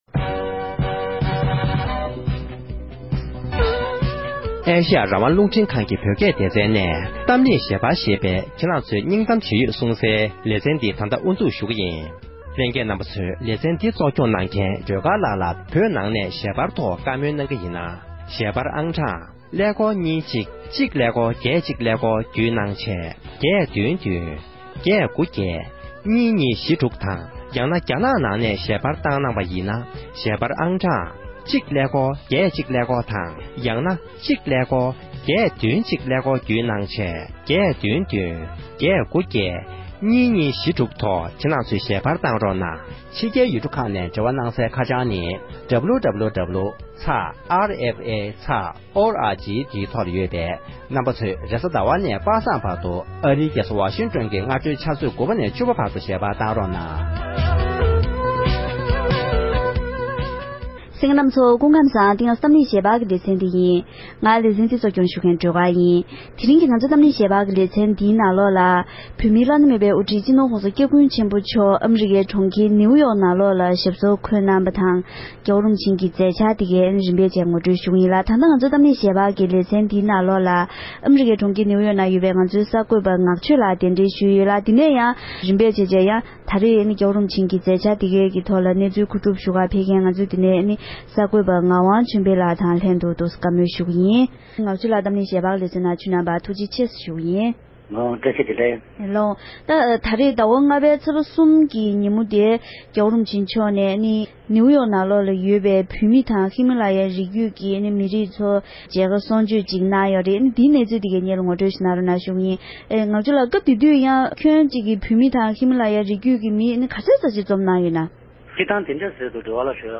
༸གོང་ས་མཆོག་གི་ནིའུ་ཡོརྐ་གྲོང་ཁྱེར་ནང་གི་མཛད་འཕྲིན་སྐོར་གླེང་མོལ་ཞུས་པ།